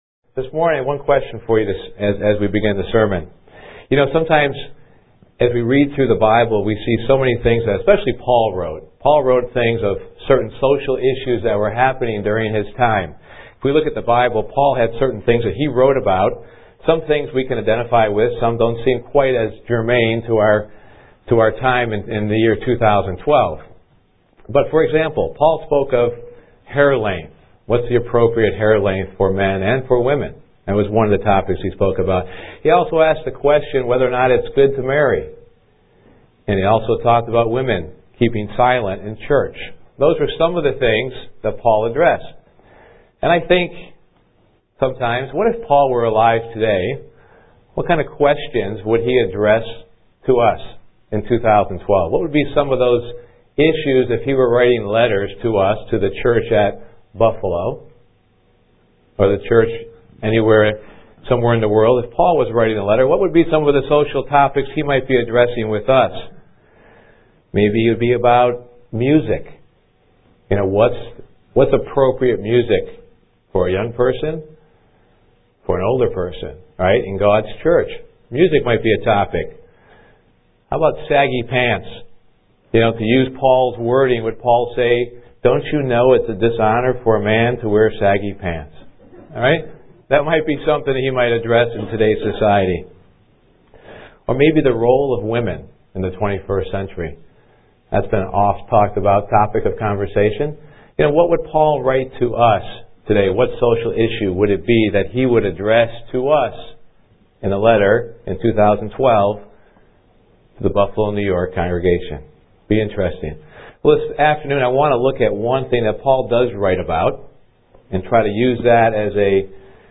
Given in Elmira, NY
UCG Sermon Studying the bible?